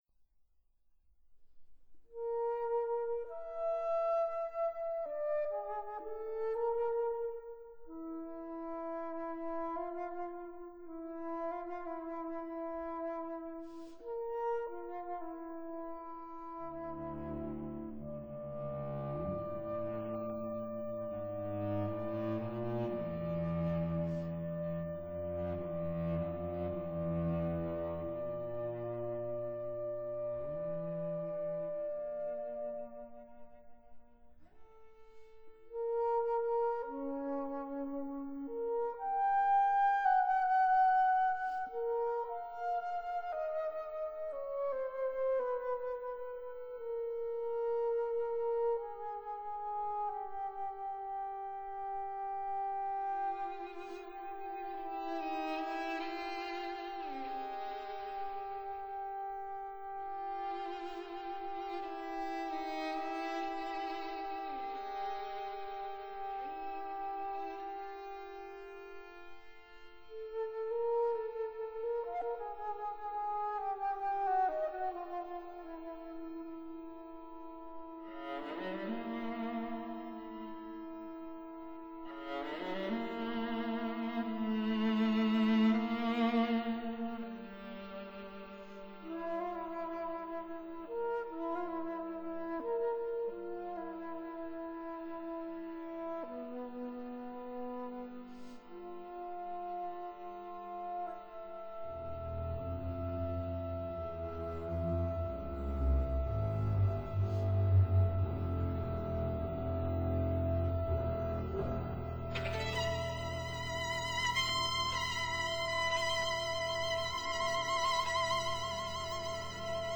piano
flute